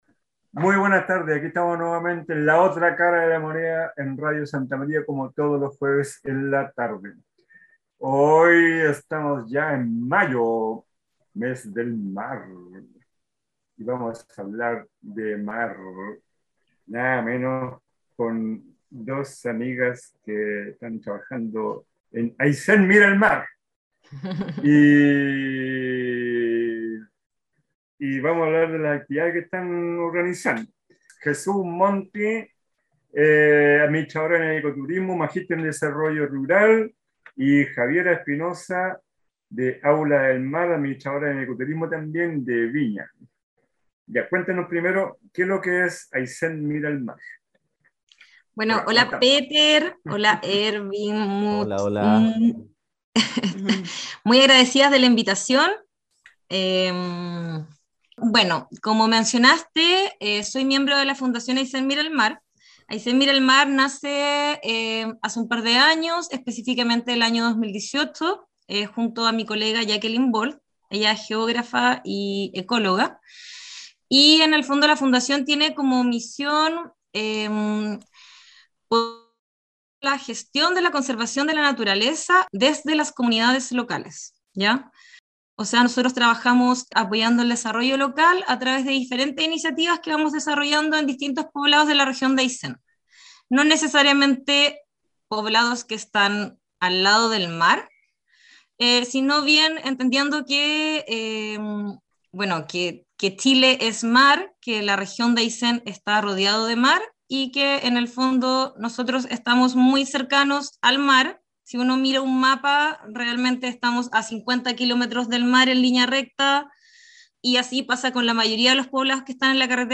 Conversamos con las administradoras en turismo